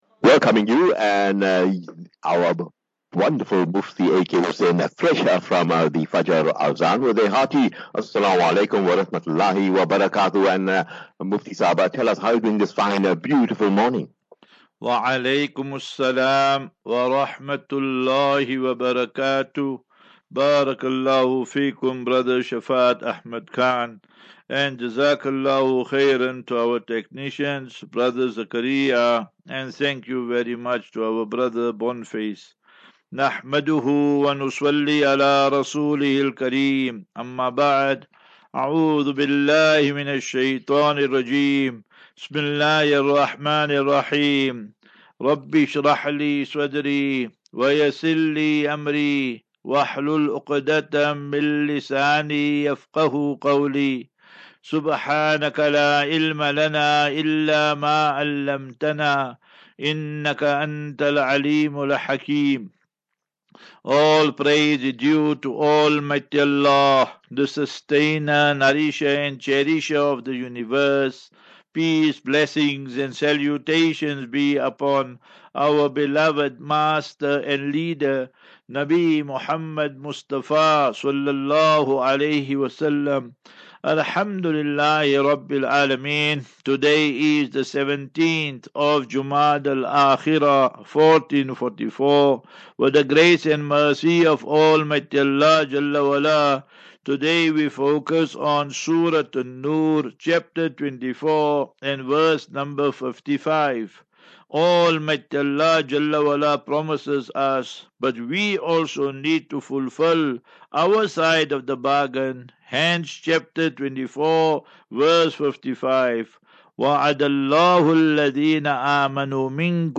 View Promo Continue Install As Safinatu Ilal Jannah Naseeha and Q and A 10 Jan 10 Jan 23- Assafinatu-Illal Jannah 47 MIN Download